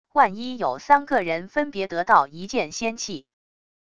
万一有三个人分别得到一件仙器wav音频生成系统WAV Audio Player